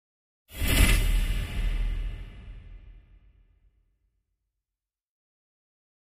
Impact Muted Hit Reverb - Version 5